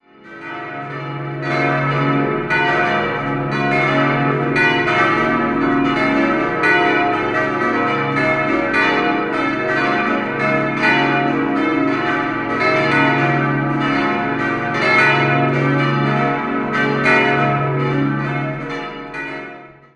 5-stimmiges Geläute: h°-d'-e'-fis'-a' Alle Glocken stammen aus der Gießerei Petit&Edelbrock in Gescher (Westfalen).